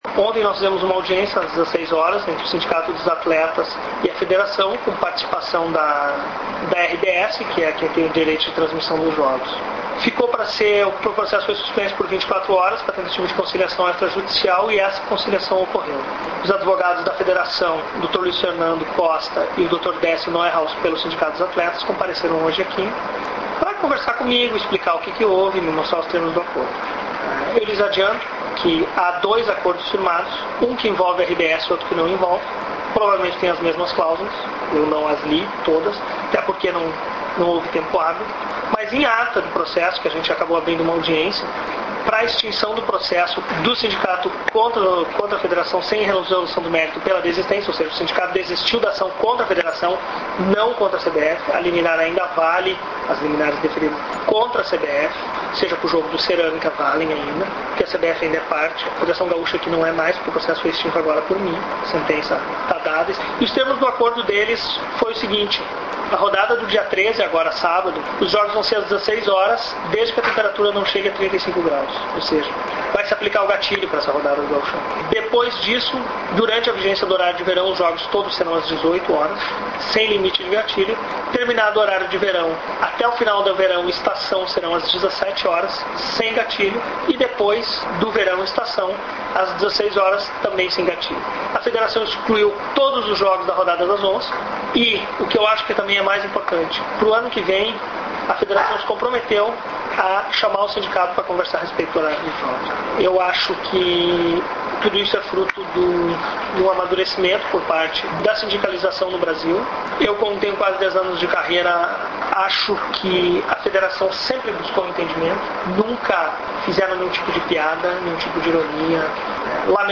Clique no ícone de áudio ao lado do título para acessar a manifestação do Juiz Rafael da Silva Marques sobre a audiência realizada hoje (10).